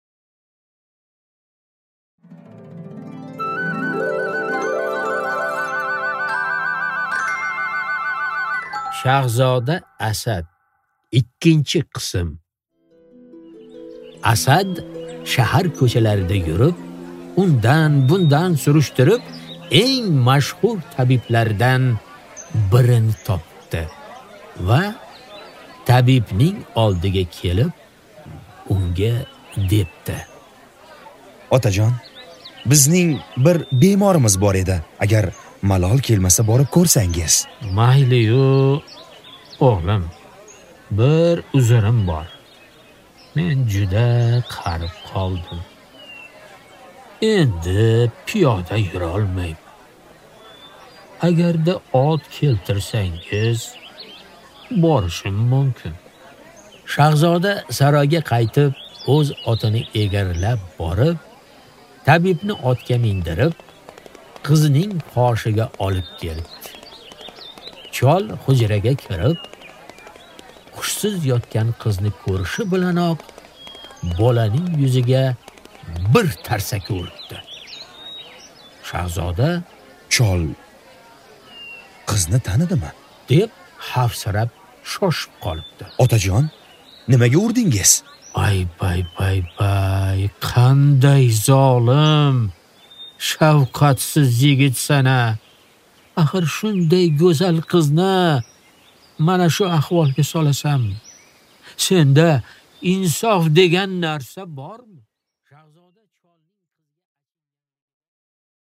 Аудиокнига Shahzoda Asad 2-qism